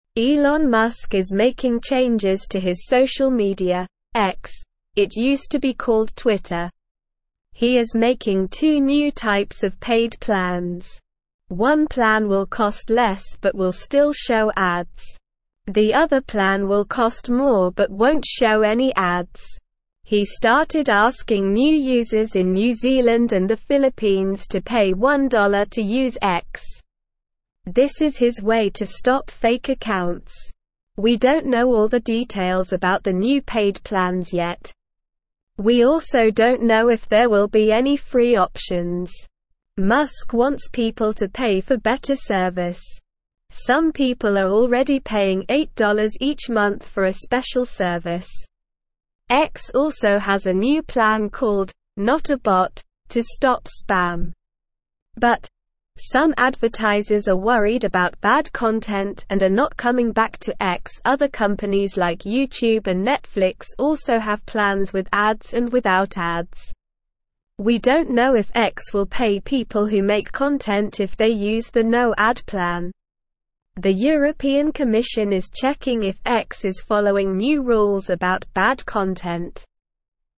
Slow
English-Newsroom-Beginner-SLOW-Reading-Elon-Musks-Social-Media-X-Introduces-Paid-Plans.mp3